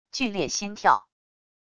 剧烈心跳wav音频